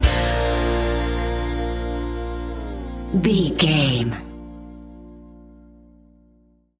Ionian/Major
A♭
acoustic guitar
electric guitar
drums
violin
Pop Country
country rock
bluegrass
happy
uplifting
driving
high energy